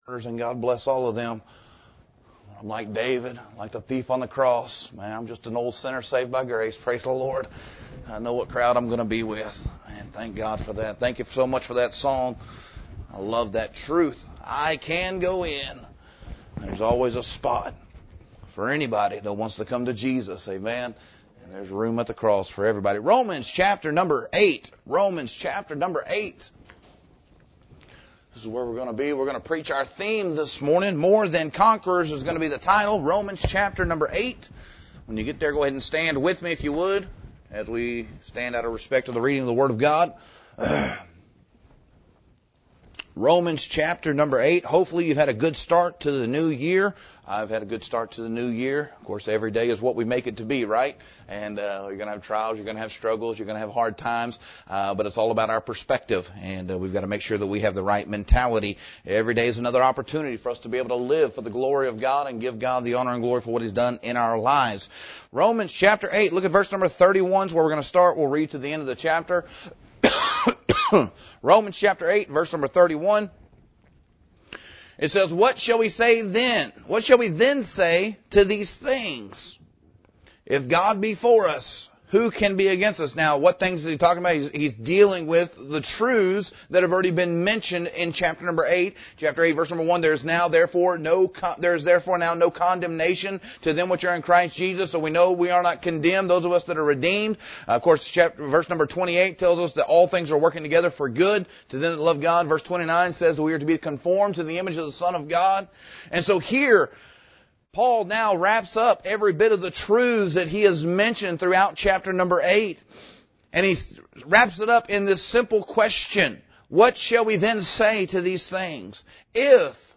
Sermon Title: “More Than Conquerors”